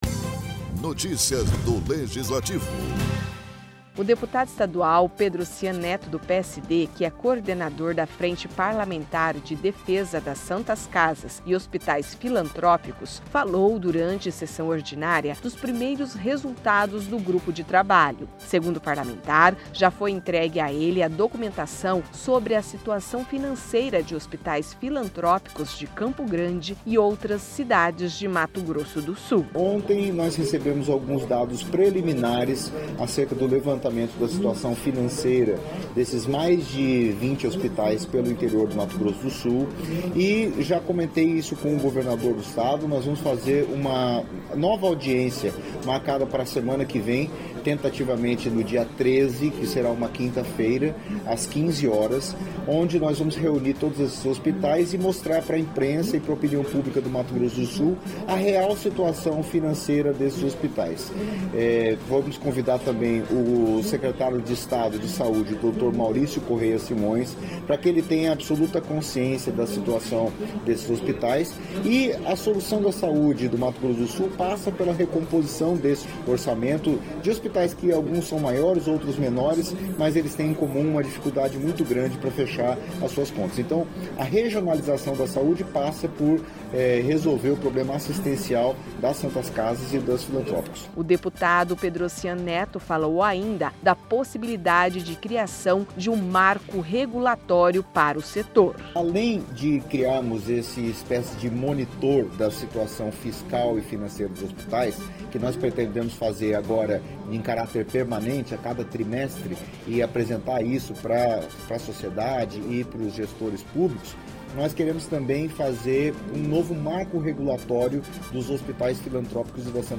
O deputado estadual Pedrossian Neto (PSD), que é coordenador da Frente Parlamentar de Defesa das Santas Casas e Filantrópicos, falou durante sessão ordinária, dos primeiros resultados do grupo de trabalho.